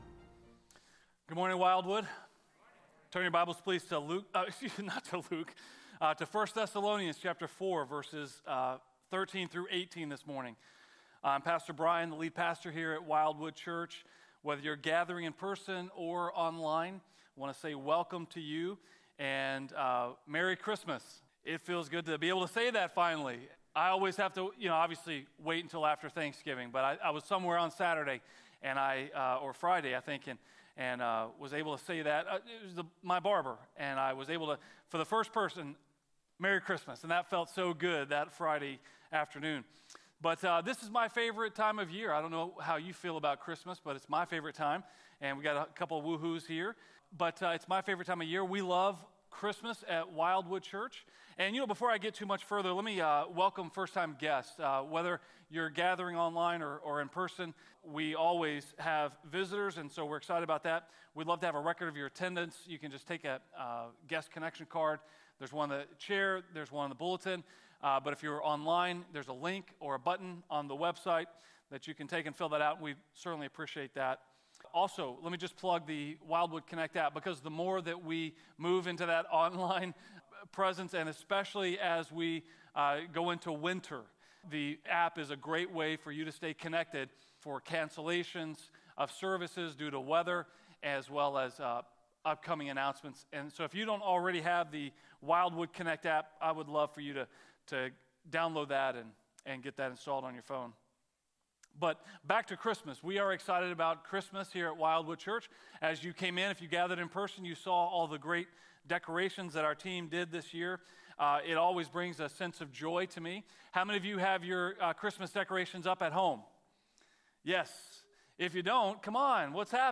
A message from the series "Promise of Christmas."